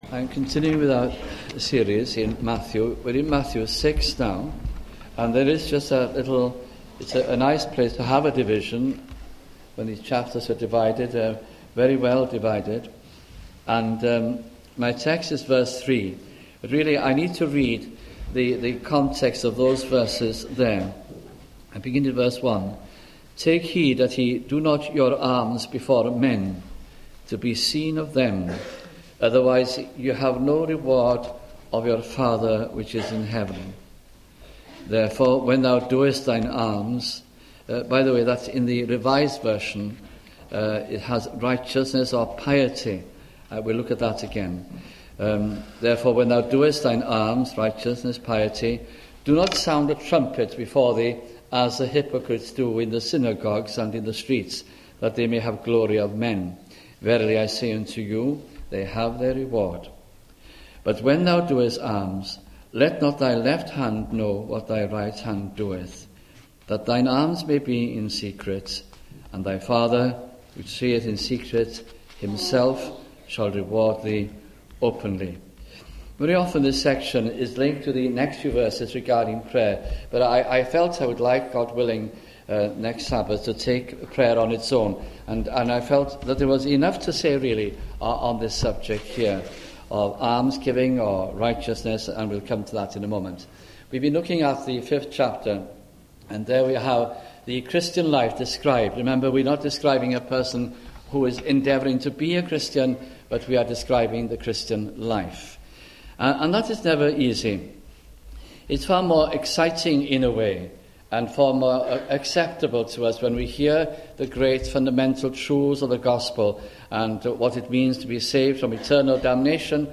Title: Sermon on the Mount 1995